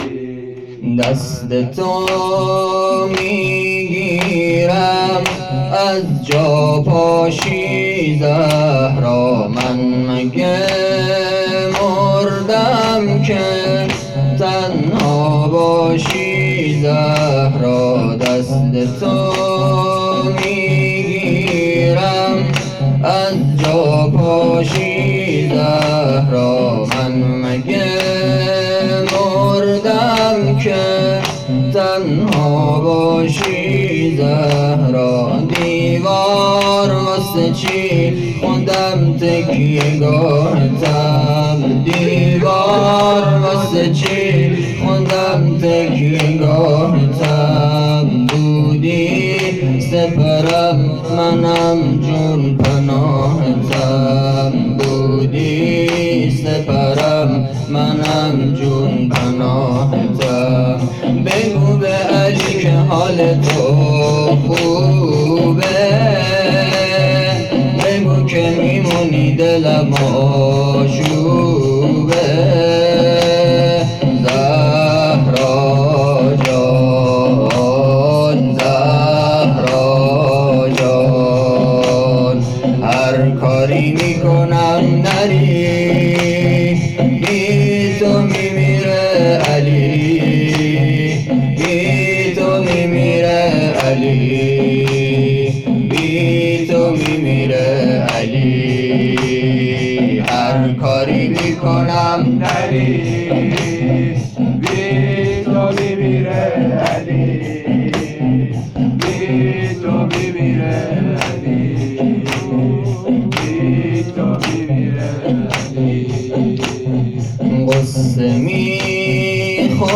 فاطمیه 1401